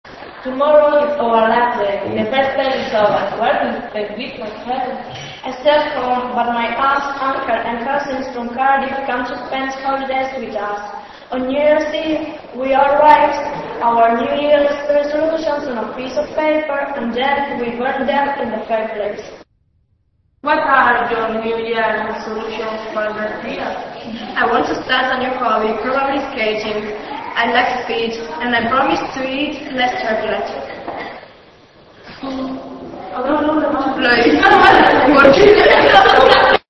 Dos chicas sentadas en aula mantienen una conversación